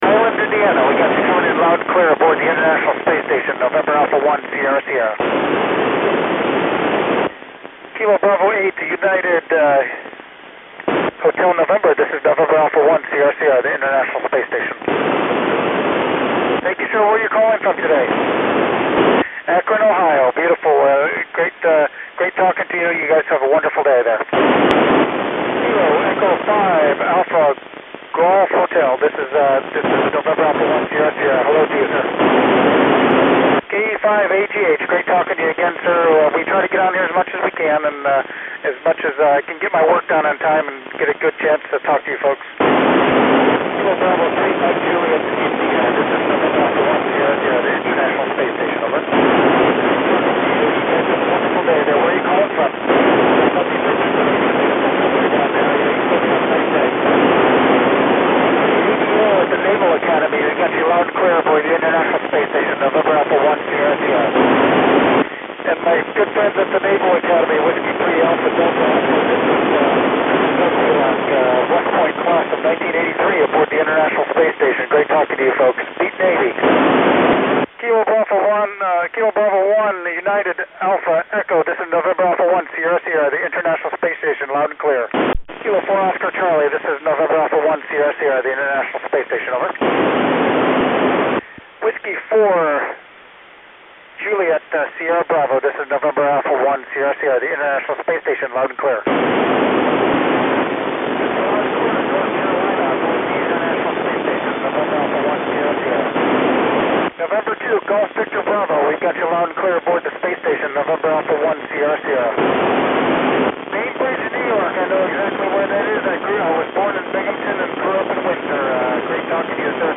NA1SS: Doug Wheelock contact made with Navel Academy! July 19 2032Z